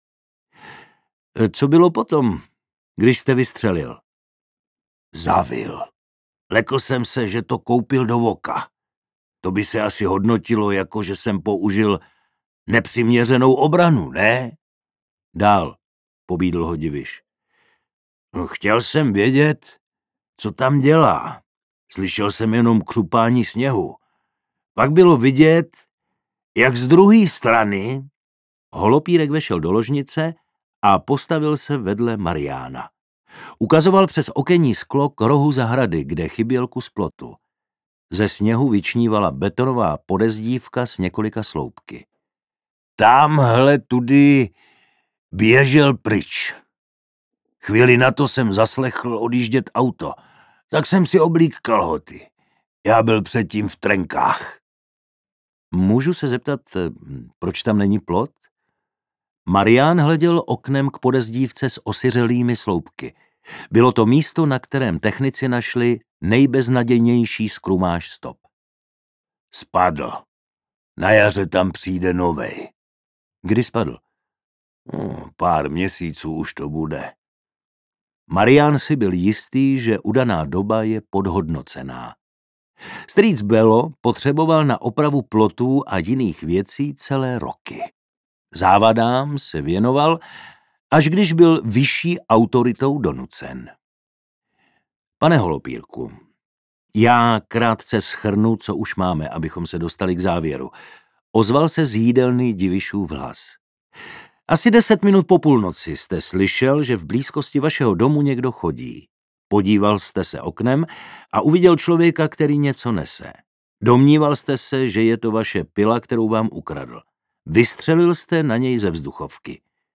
Délka: 12 h 50 min Interpret: Jana Stryková Vydavatel: Témbr Vydáno: 2023 Série: Jessica Blackwood díl 2 Jazyk: český Typ souboru: MP3 Velikost: 724 MB